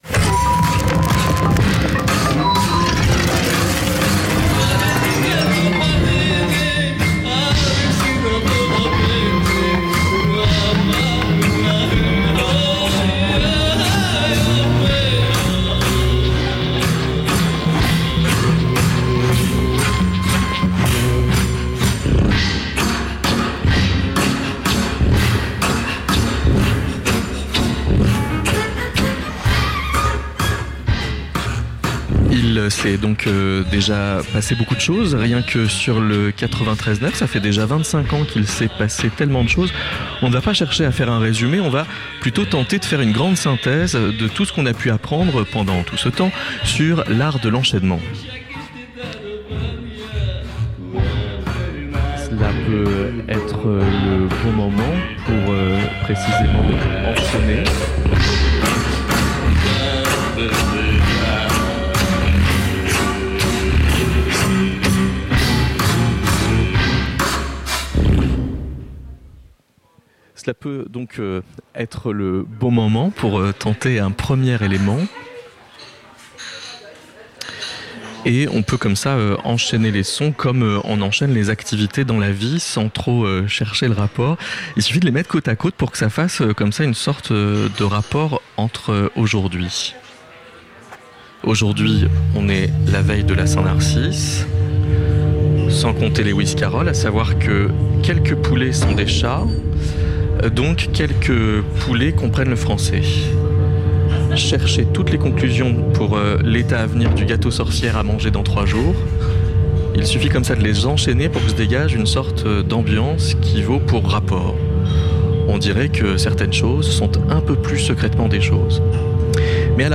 25h de direct en 24h - Radio Campus Paris